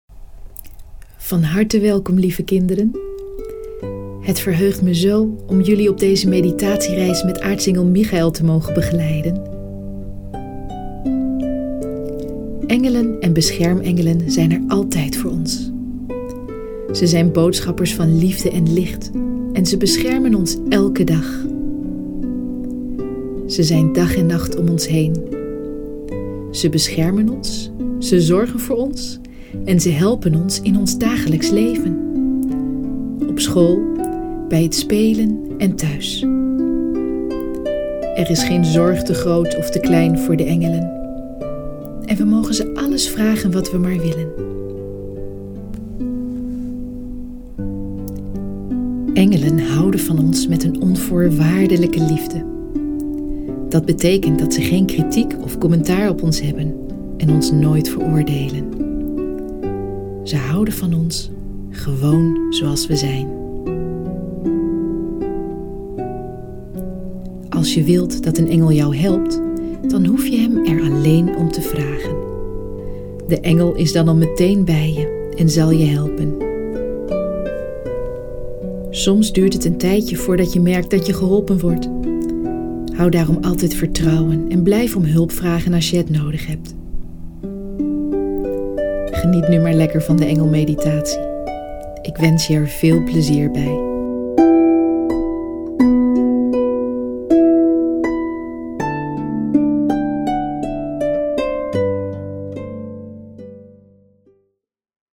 3 meditaties voor kinderen
Luisterboek
De drie meditaties worden ondersteund door helende muziek, die de therapeutische werking van de meditaties versterkt.